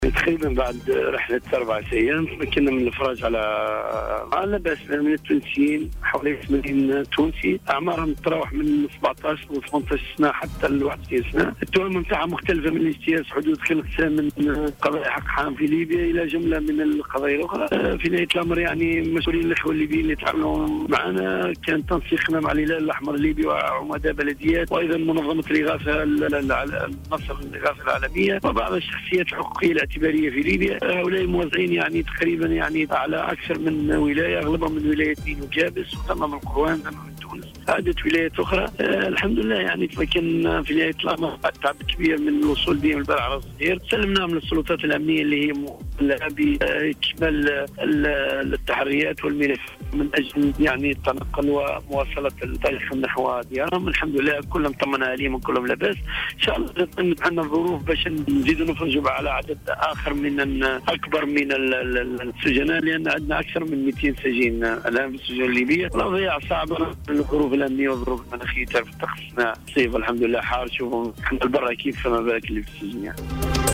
في اتصال هاتفي اليوم مع "الجوهرة أف أم"